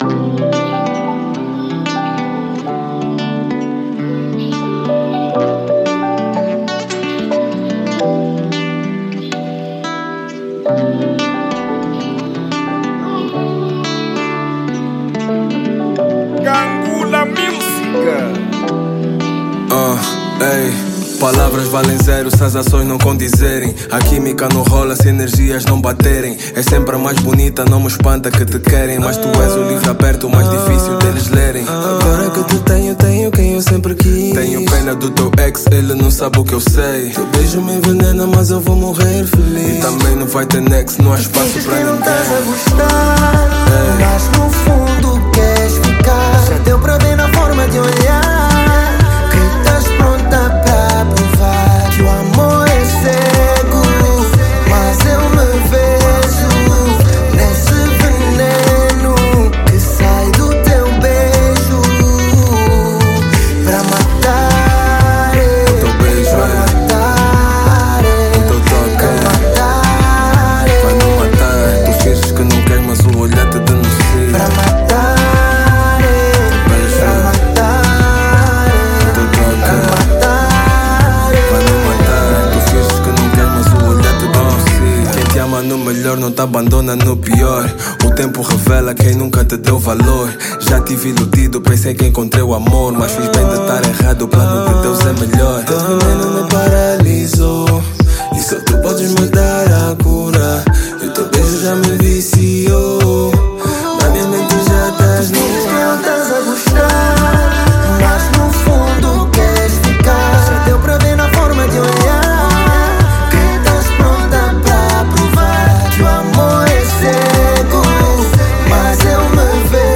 | Pop RnB